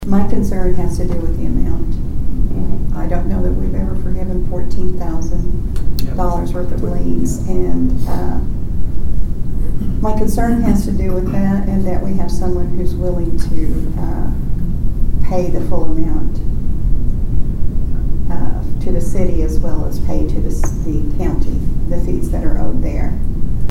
Council member Billie Roane on her concern.